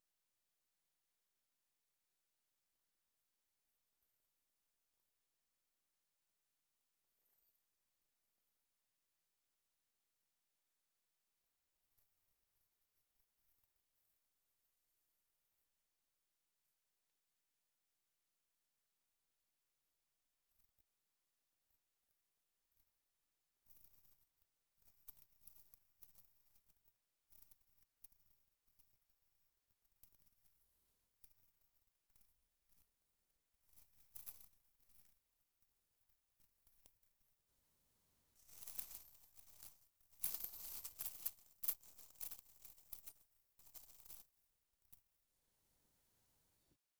Royalty-free decay sound effects
attack--sound-1--nzctstag.wav